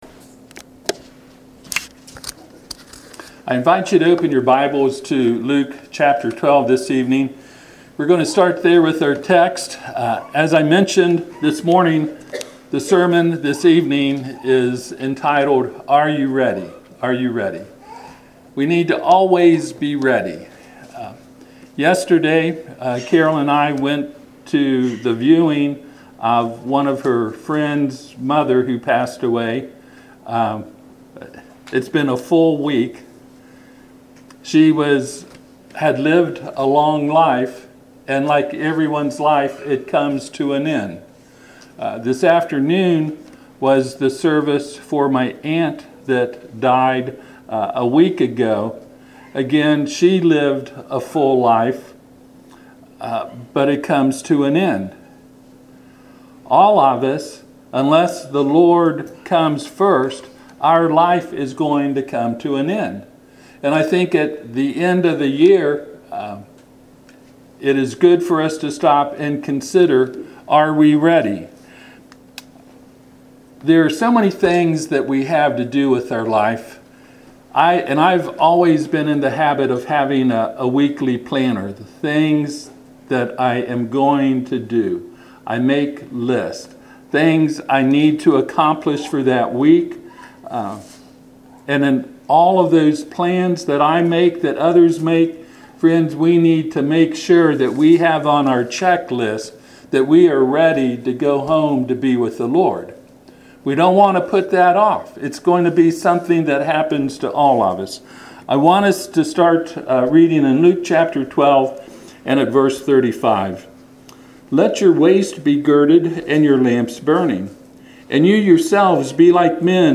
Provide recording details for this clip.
Passage: Luke 12:35-48 Service Type: Sunday PM